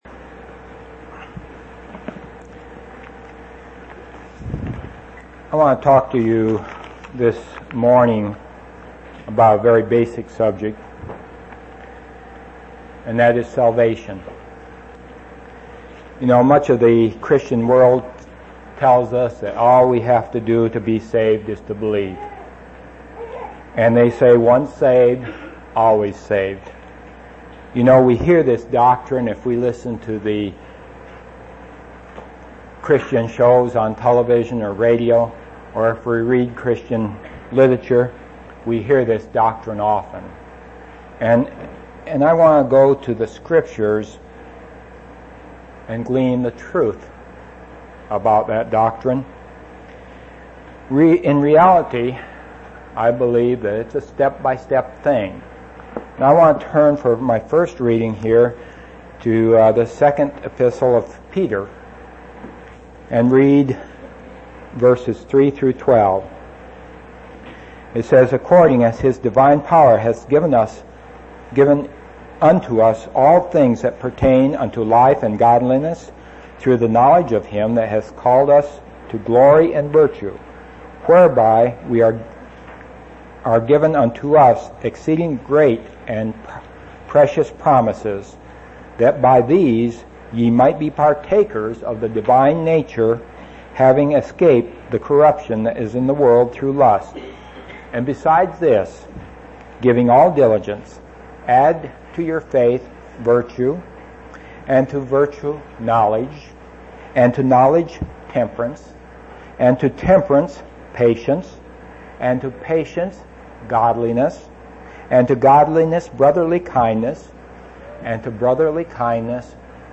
9/8/1996 Location: East Independence Local Event